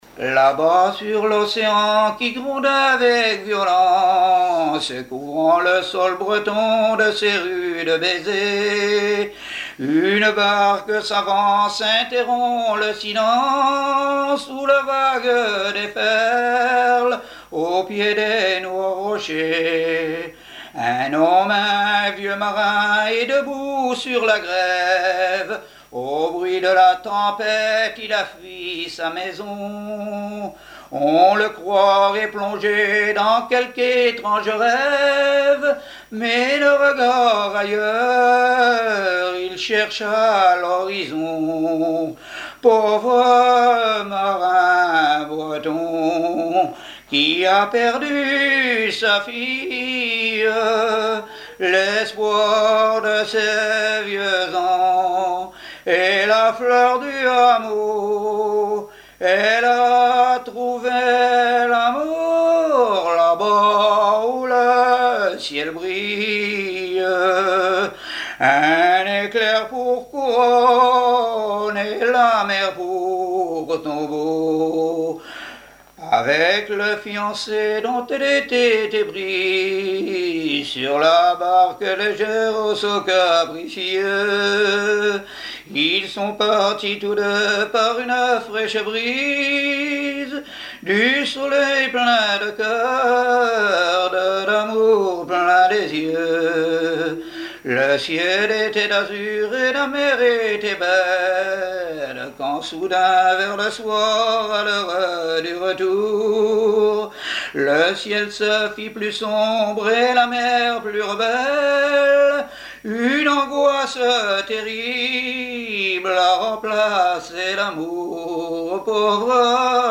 Genre strophique
répertoire de chansons et témoignages
Pièce musicale inédite